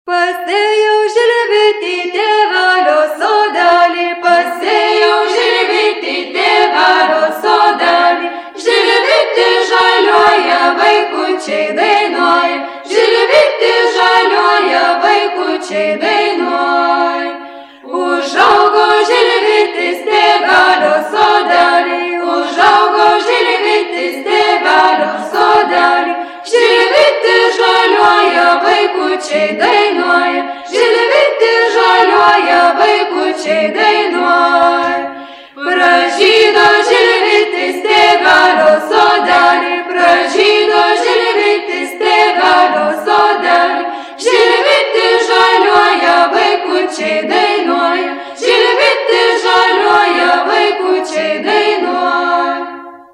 FOLKLORE> DANCES> Circles
It is a widely spread circle in Lithuania, danced in couples.